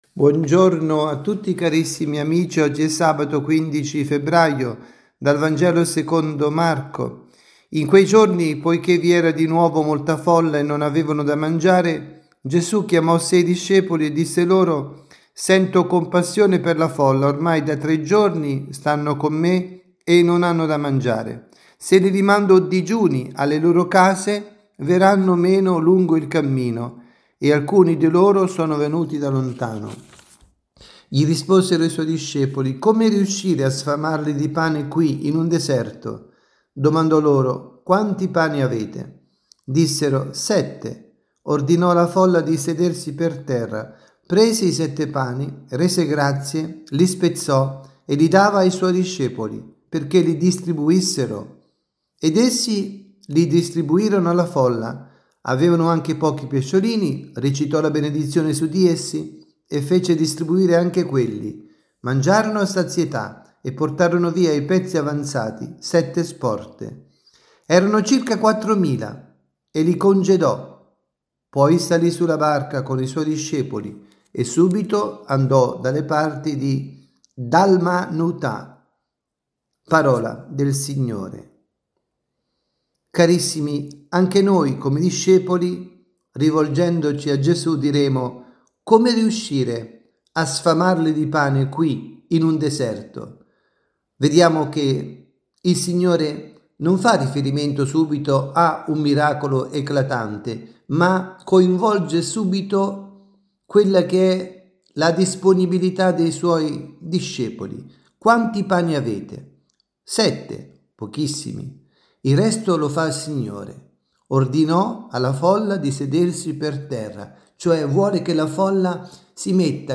avvisi, Catechesi, Omelie, Ordinario